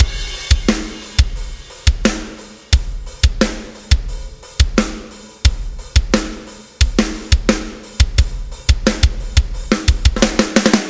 Metal Mayhem3 HEAVY DRUMS1 176
Tag: 176 bpm Heavy Metal Loops Drum Loops 1.84 MB wav Key : Unknown Reason